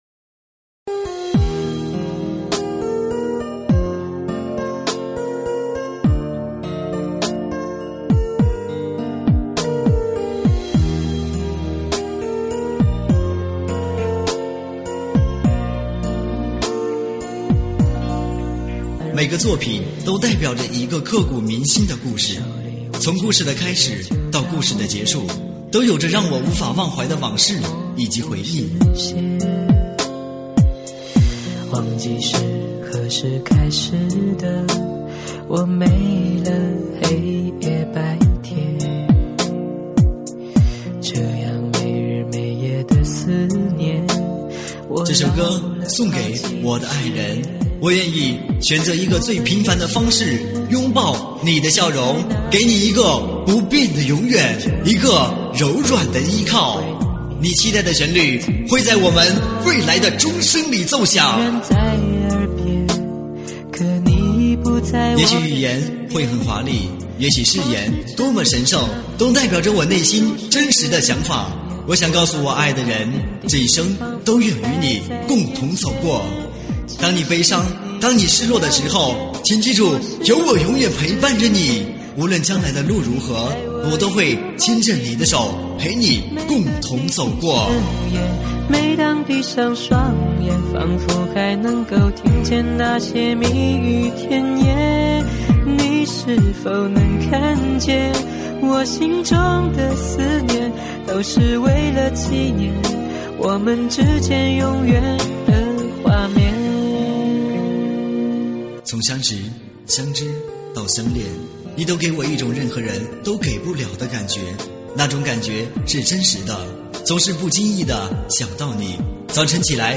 舞曲类别：喊麦现场